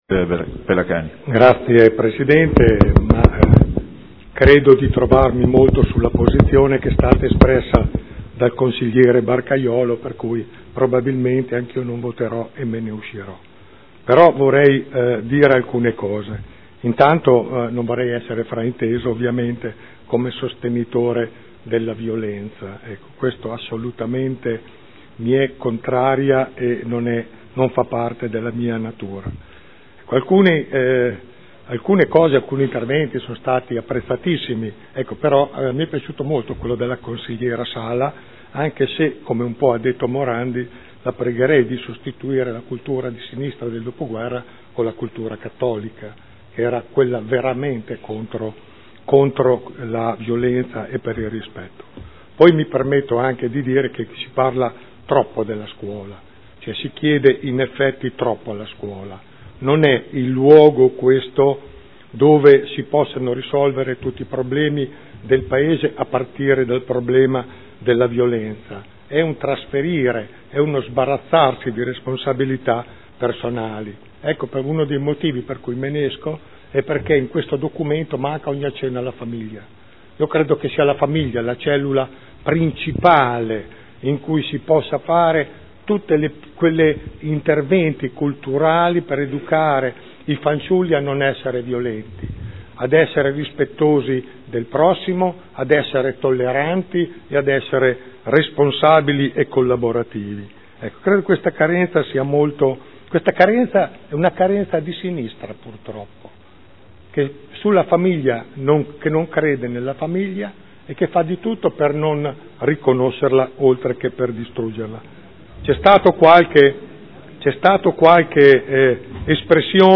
Gian Carlo Pellacani — Sito Audio Consiglio Comunale
Seduta del 27/05/2013. Dichiarazione di voto.